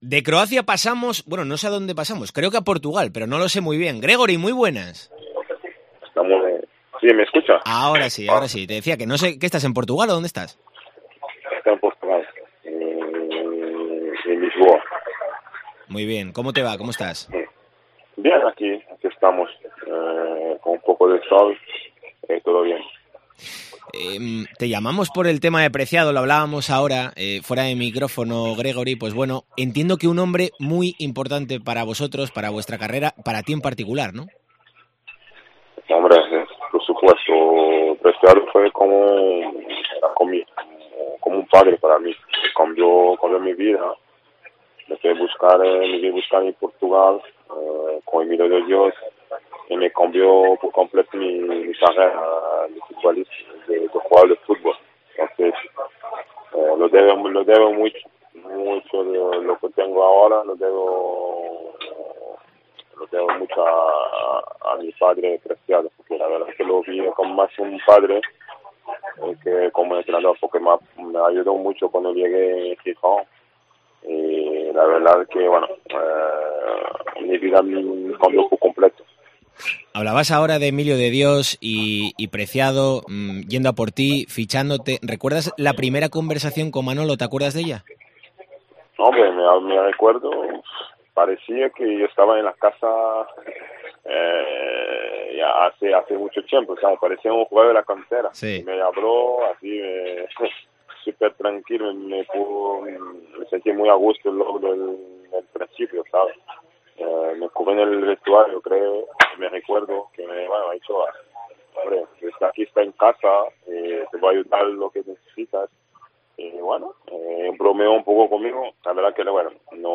Desde Portugal, donde desarrolla su labor como agente de futbolistas, Gregory Arnolin atiende la llamada de Deportes COPE Asturias en este 6 de junio de 2022, cuando se cumplen 10 años del fallecimiento de Manolo Preciado.